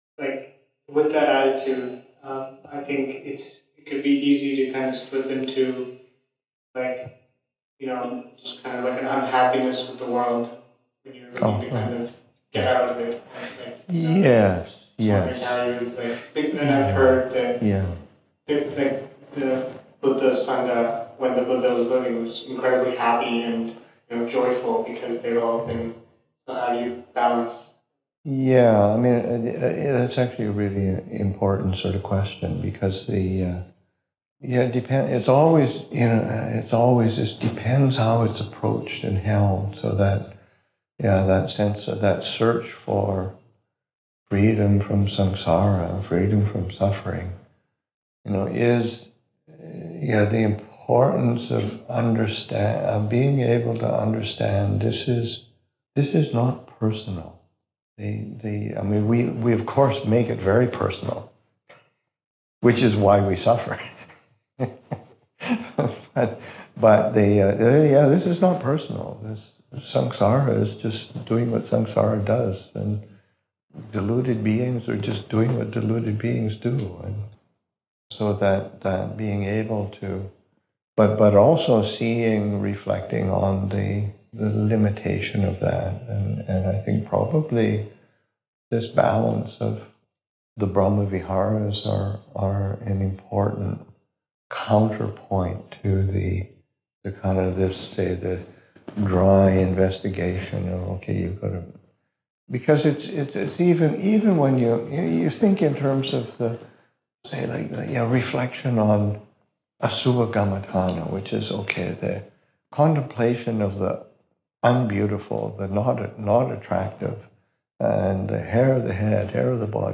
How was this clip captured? Questions and Answers at Wat Pah Nanachat – Jan. 2, 2025